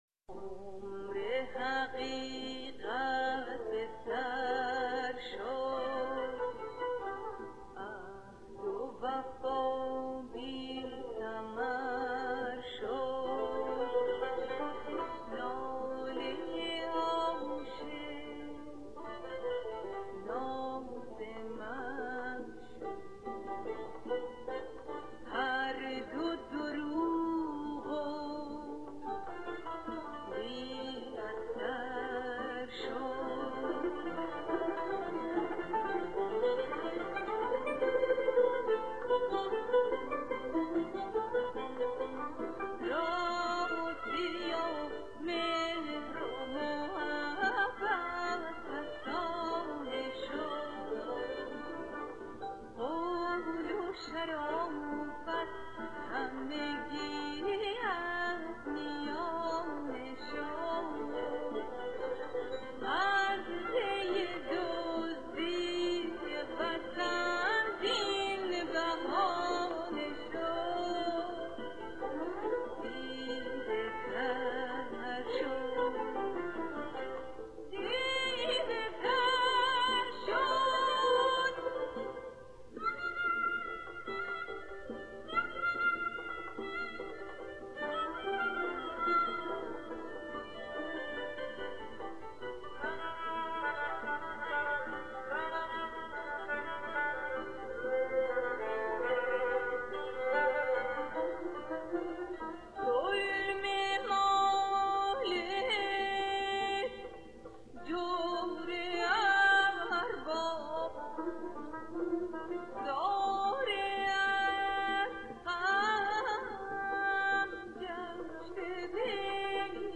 Audio2  sung